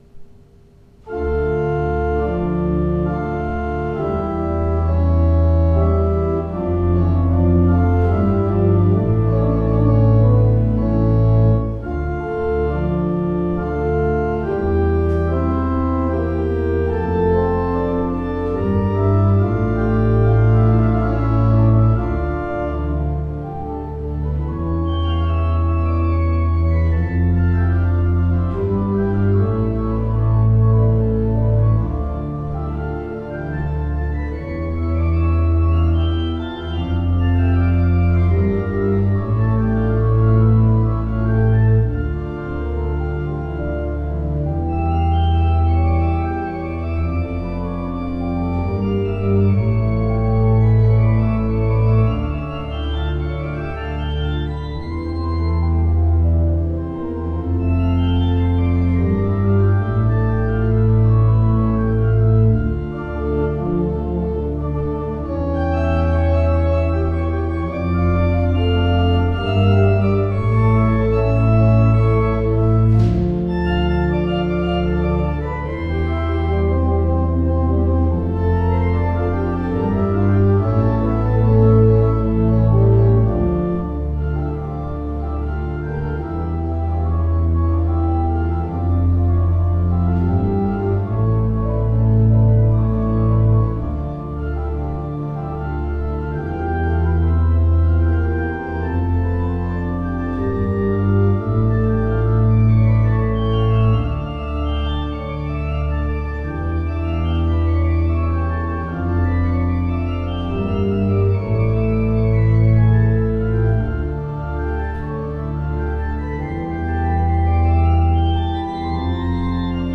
Postludes played at St George's East Ivanhoe 2016
The performances are as recorded on the Thursday evening prior the service in question and are made using a Zoom H4 digital recorder.